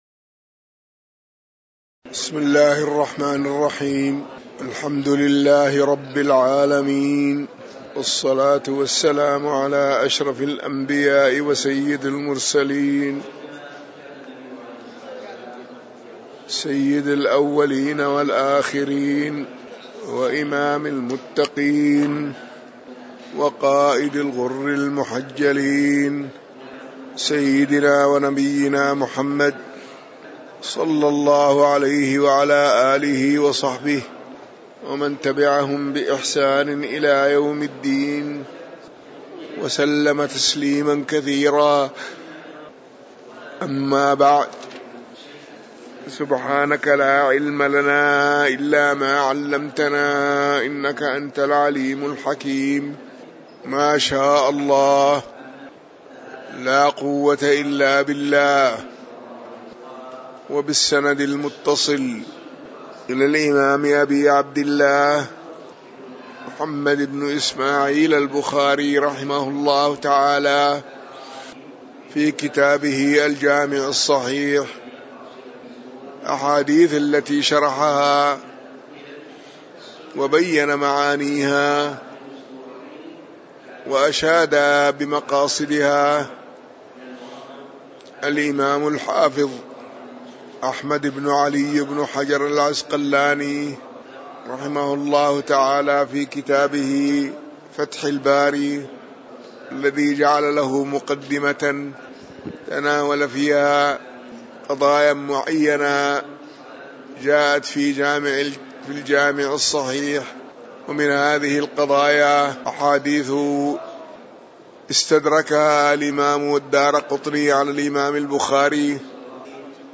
تاريخ النشر ٢٣ محرم ١٤٤١ هـ المكان: المسجد النبوي الشيخ